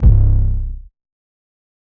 808_Oneshot_Spun_C.wav